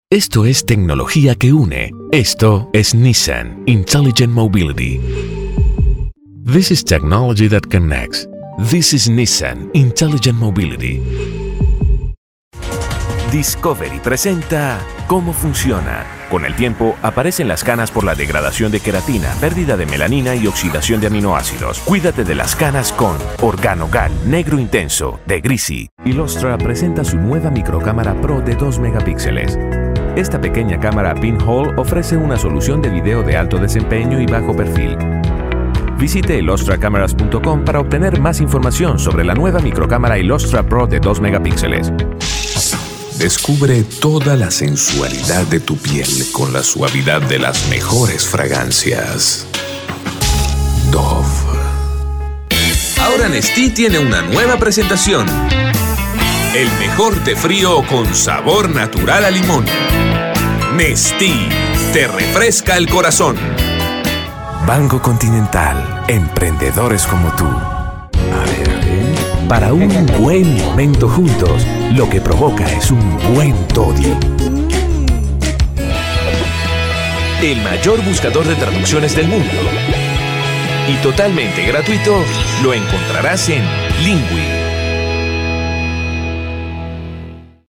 Female Voice Over, Dan Wachs Talent Agency.
Friendly, Warm, Conversational.
Commercial